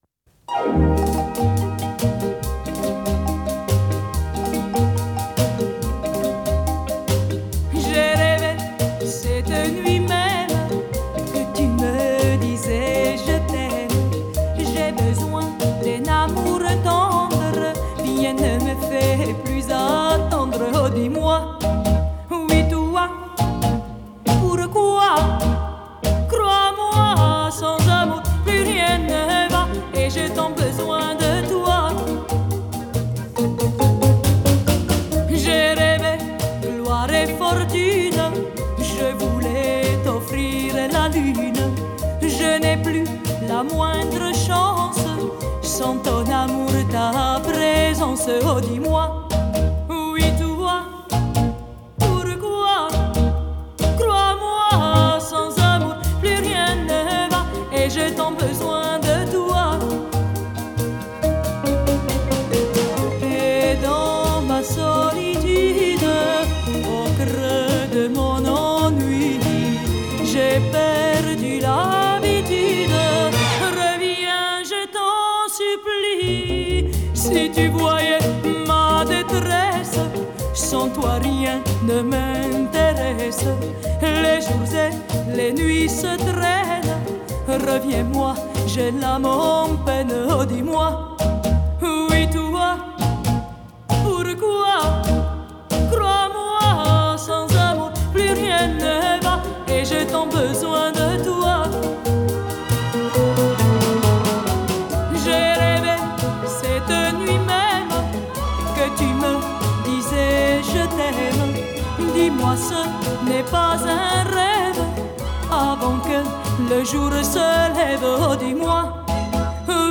Genre: Variete Francaise, Pop Vocale